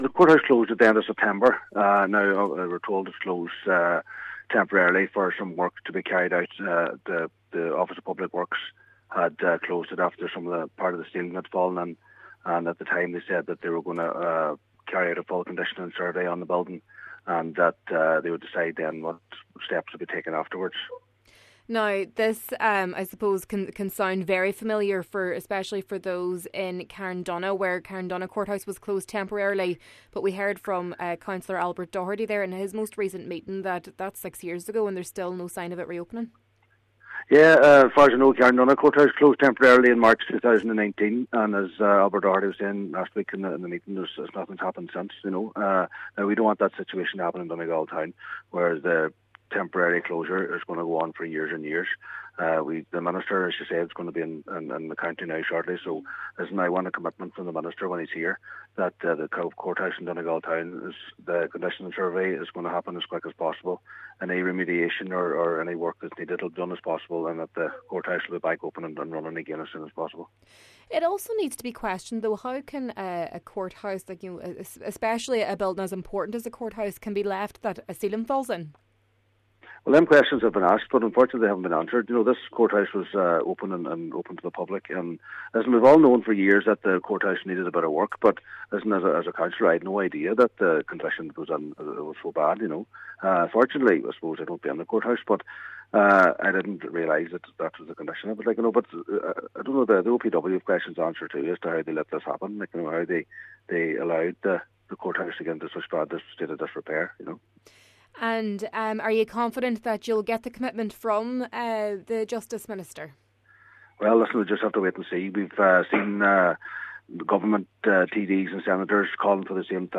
Local Cllr Jimmy Brogan says he will ask Minister Jim O’Callaghan for a commitment that the courthouse will not suffer the same fate as the one in Carndonagh, which was temporarily shut six years ago: